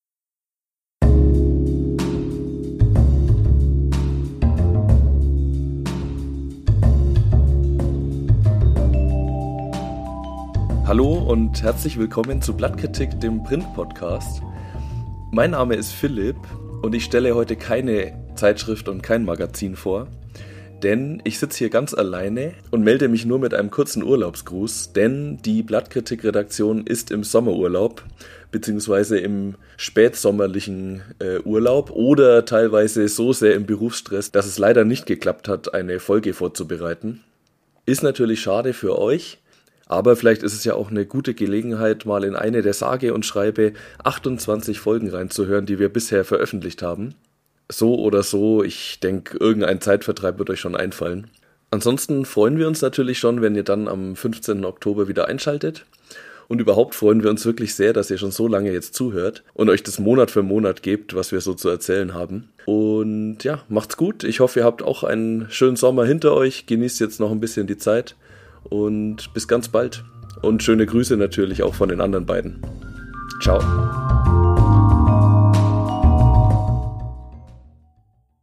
Music: "Bass Meant Jazz" by Kevin MacLeod, licensed under CC0 1.0